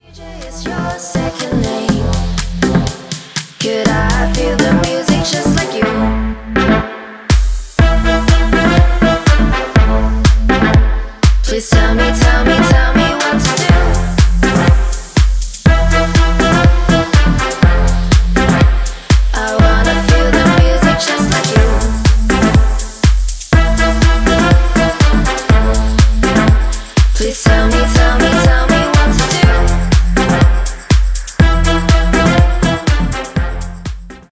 • Качество: 320, Stereo
громкие
женский вокал
dance
future house
house